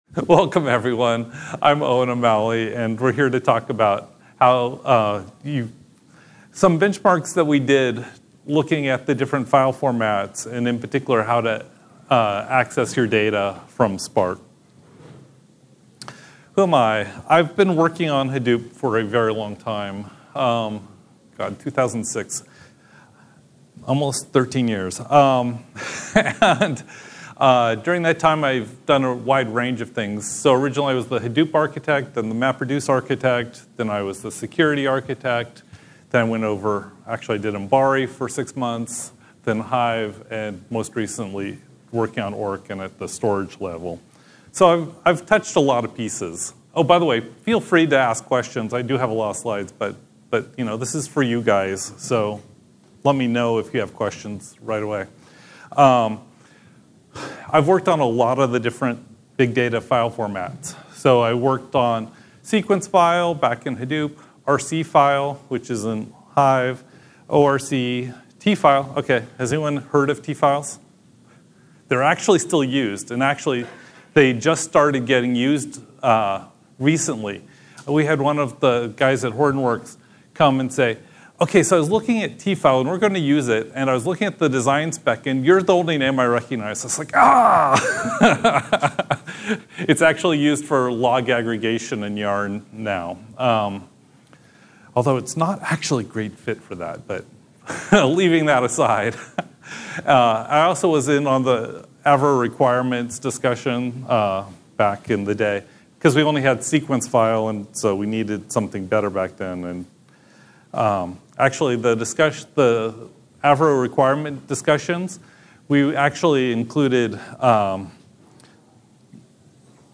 Tags: ApacheCon, apacheconNA2018, Podcasts • Permalink